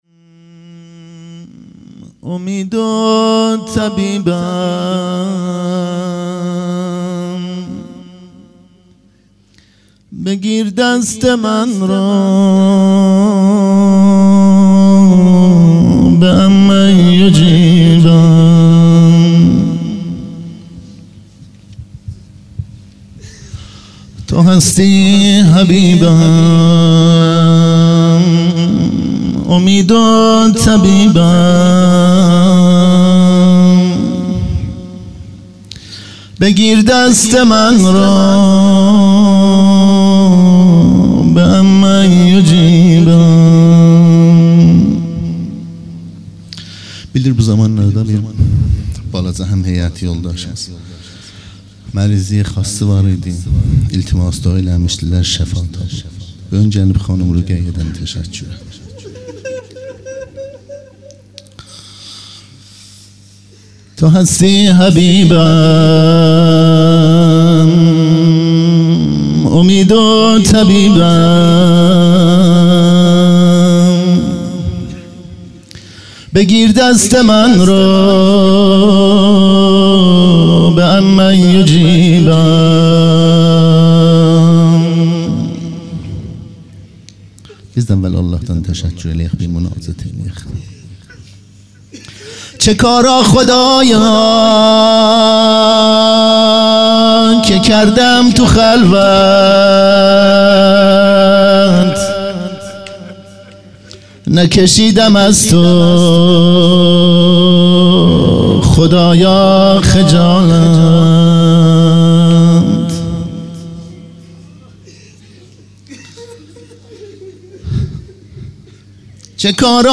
هیئت مکتب الشهداء خوی - شب سوم محرم1440- روضه